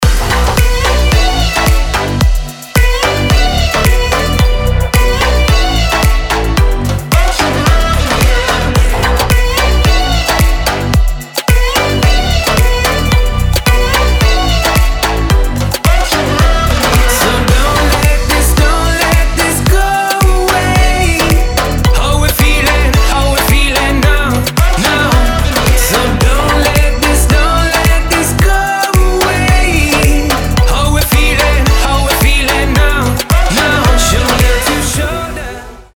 • Качество: 320, Stereo
поп
club
house
vocal